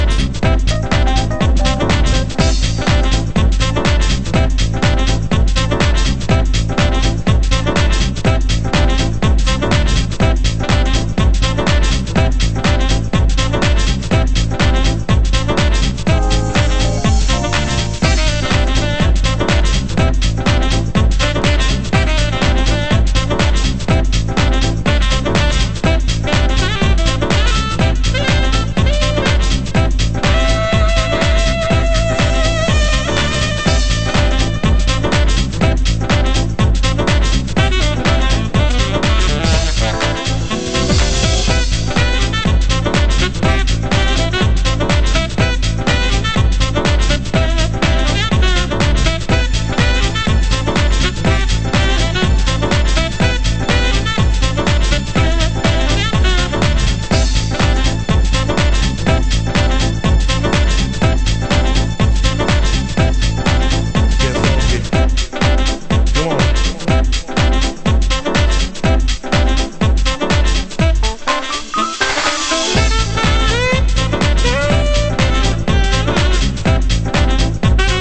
盤質：少しチリノイズ有　　ジャケ：良好